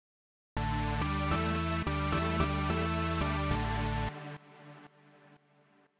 А в звуке слышится нечто вроде звука органа, откуда он там, там же пилы одни в осциляторах?. Вложения LD Trance Legacy mod.wav LD Trance Legacy mod.wav 1,5 MB · Просмотры: 145